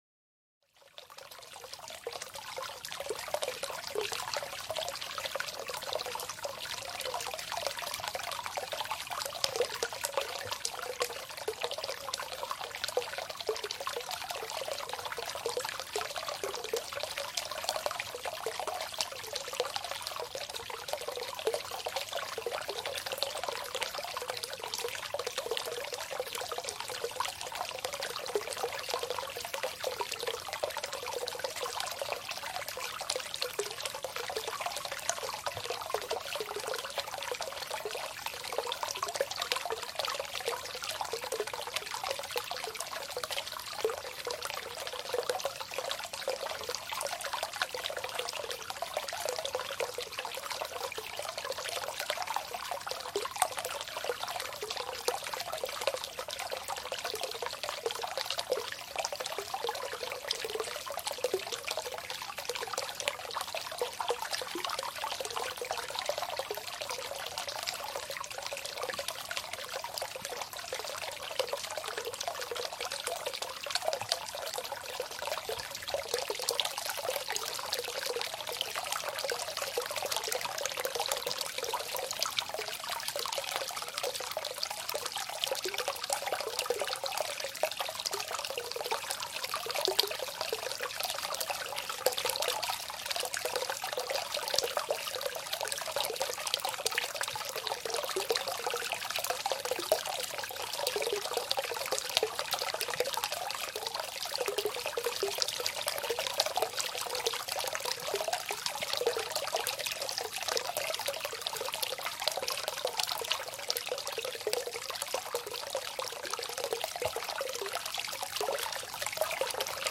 ASMR Onsen ♨ Nächtliches Quellwasser | 1/f-Fluktuation für totale Entspannung